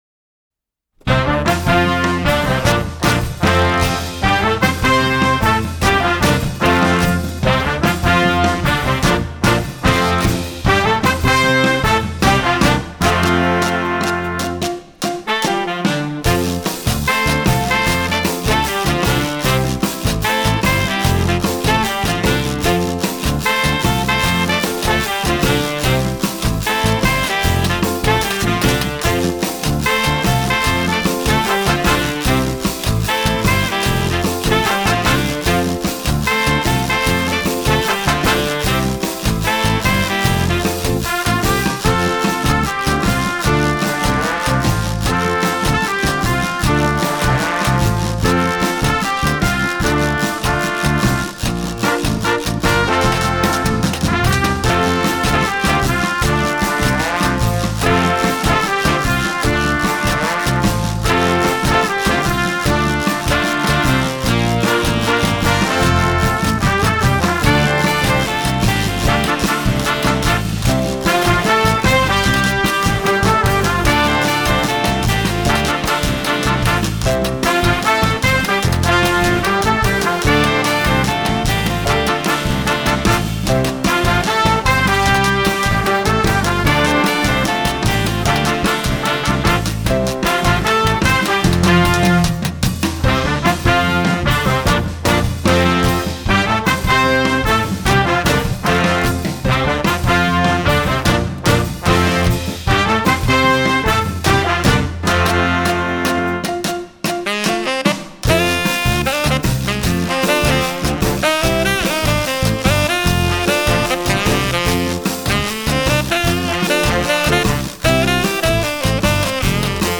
Jazz Band
Instrumentation is 5 saxes, 6 brass, 4 rhythm.